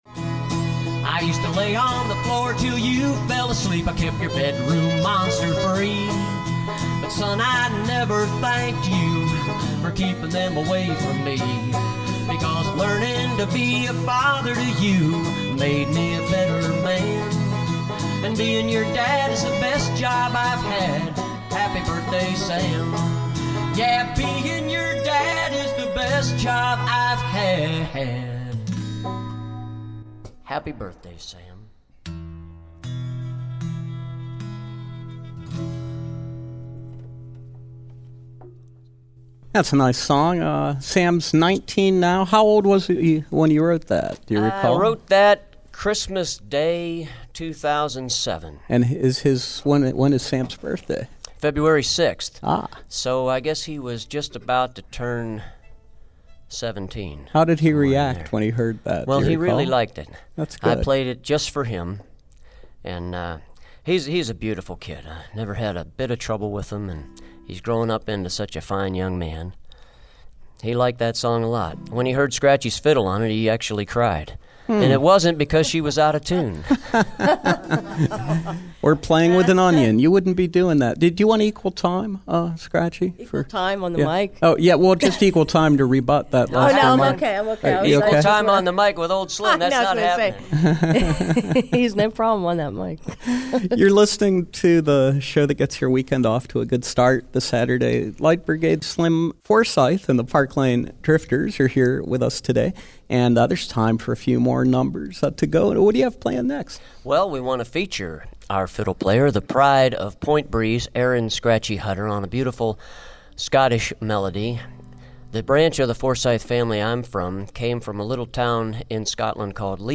Scottish fiddle tune Flowers of Edinburgh